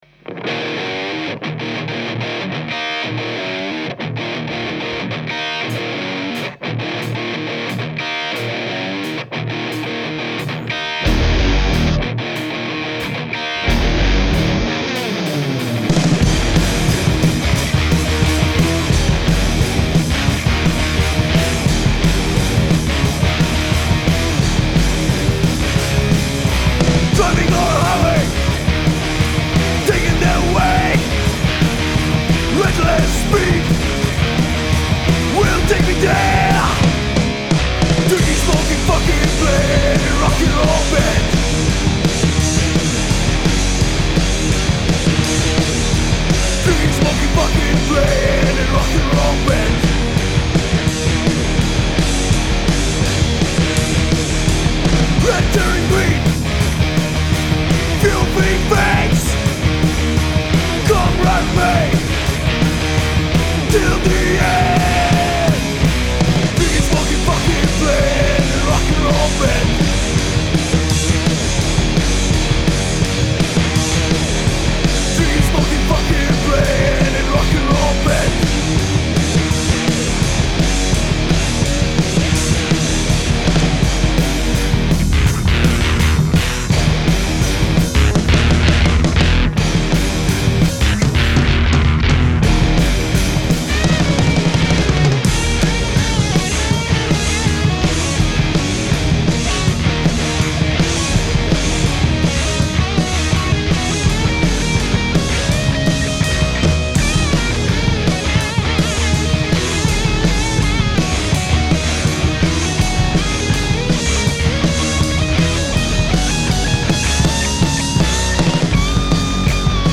Gramy mocną muzykę gitarową.
Gatunek: Metal
wokal, gitara
perkusja